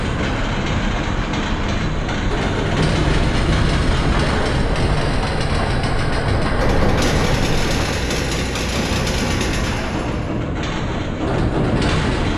CogsLoop.ogg